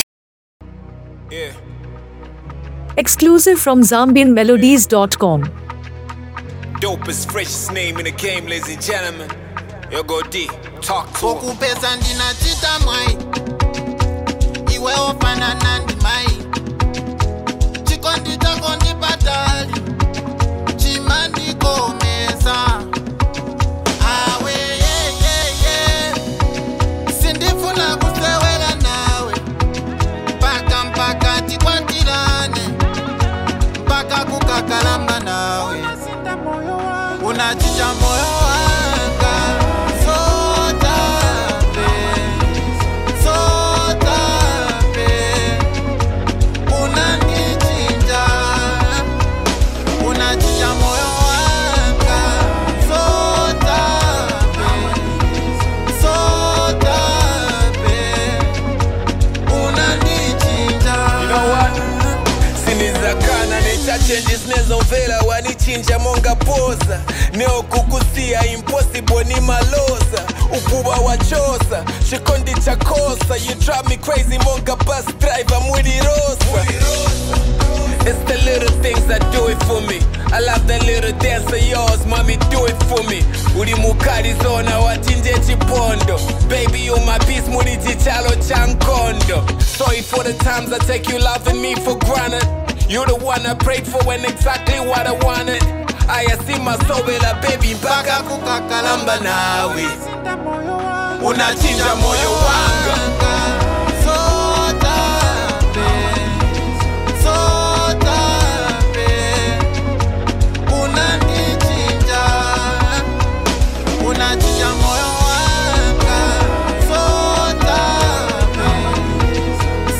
” a powerful and uplifting track.
signature rap style
soulful vocals
gospel-infused song